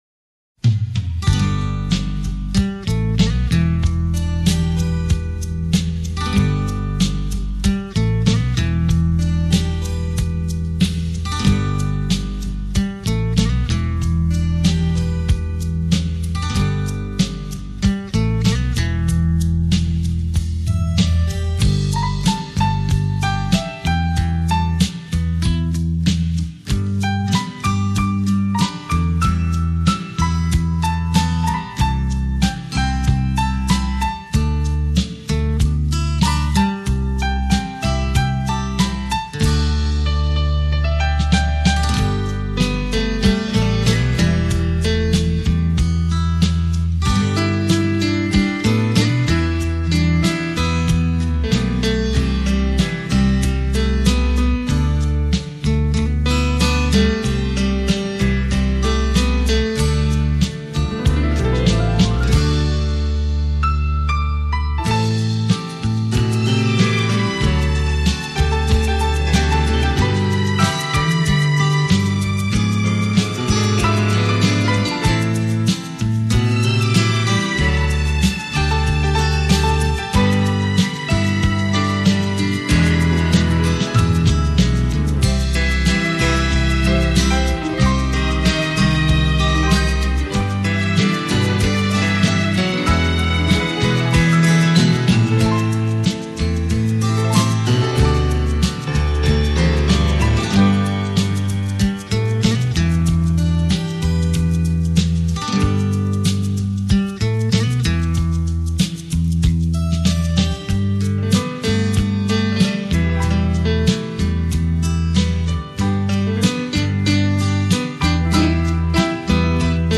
黑胶3D音效
品位完美无暇的音乐，唯美典雅的钢琴世界，
给人一种清透心扉的淡雅之美……